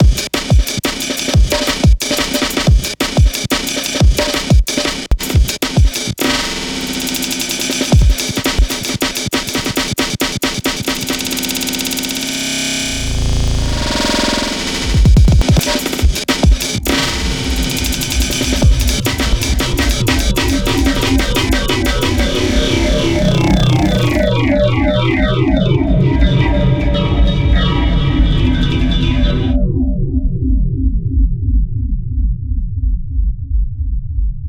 On DISC device, modulate parameters Length negatively and Tempo positively while keeping Density low and Length size relatively low and you have a stretched sample.
Also, if you pitch RING all the way down, choke Decay (about 40% in this case), Tilt all the way down, Detune to the max and use Waves a bit shy of max (I used 80%), you get a kind of flanger/APF/spectral effect.